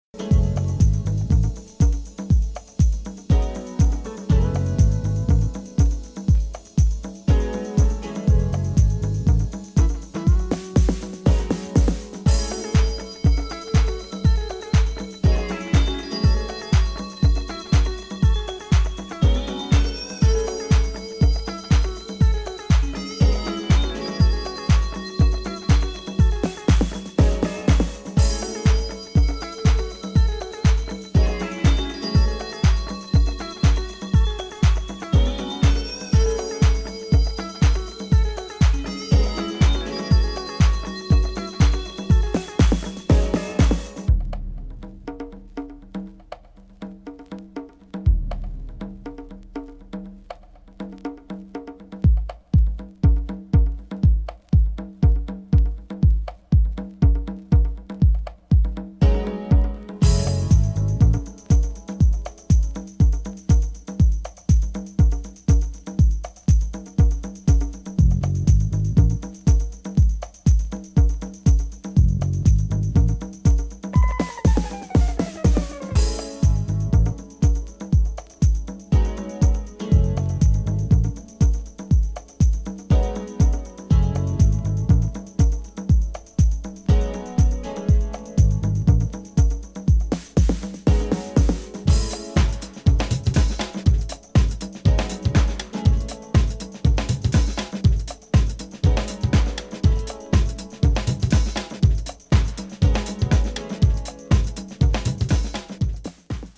'92年NYディープ・ハウス・クラシック・マスターピース！！